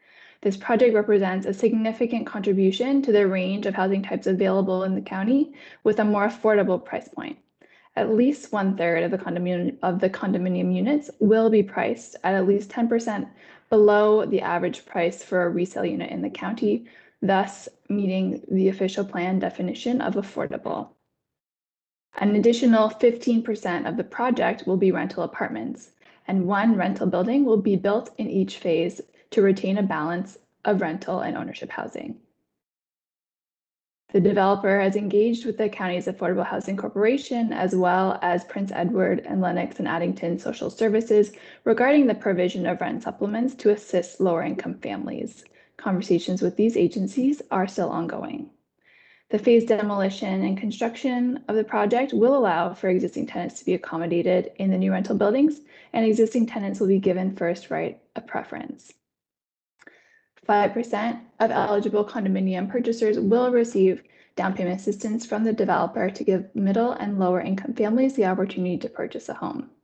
Tuesday night the county hosted a virtual public information session to detail the third iteration of the housing development near Picton.